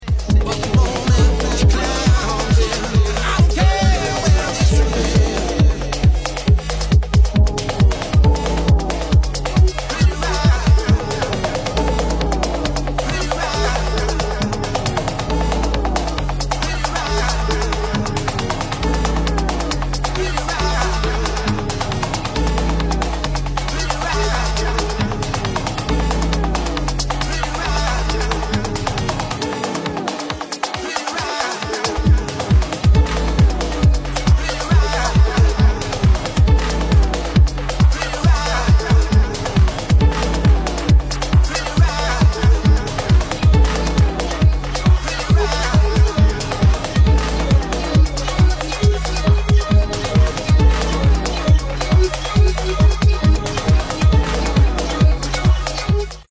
Vintage Detroit Techno/House Remix